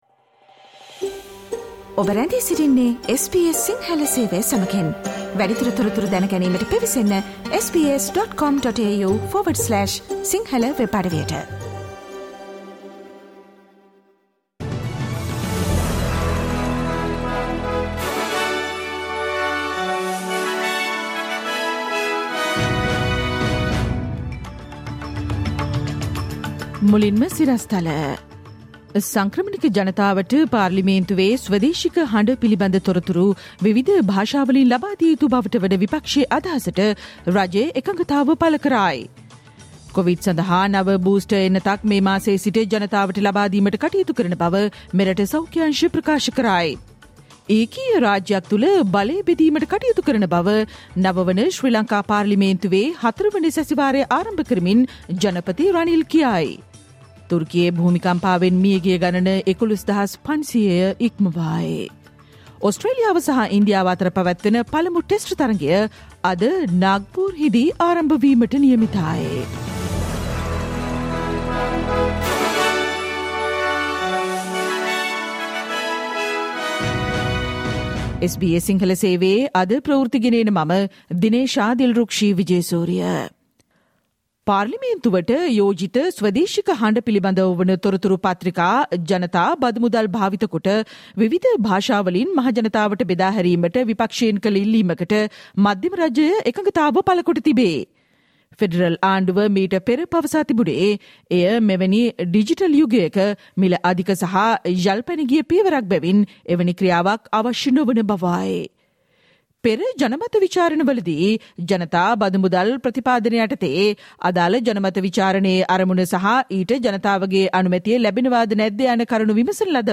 සවන්දෙන්න, අද - 2023 පෙබරවාරි 09 වන බ්‍රහස්පතින්දා SBS ගුවන්විදුලියේ ප්‍රවෘත්ති ප්‍රකාශයට